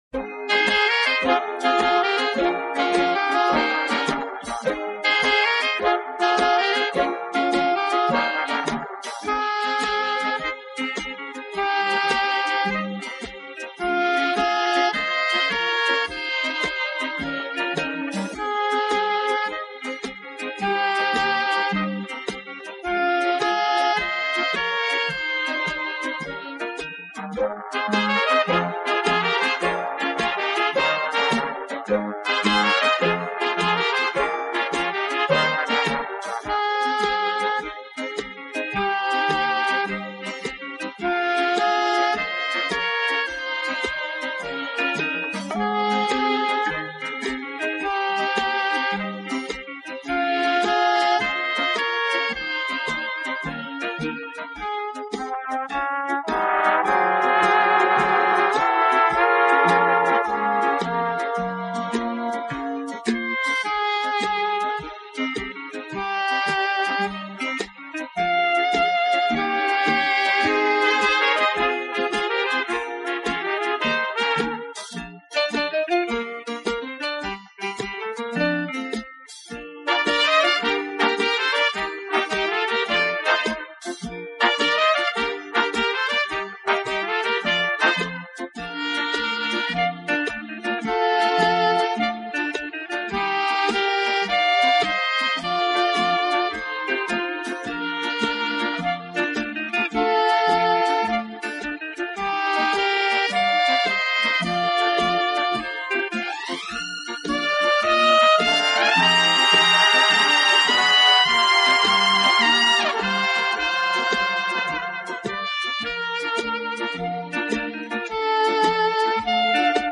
【轻音乐】
【轻音乐专辑】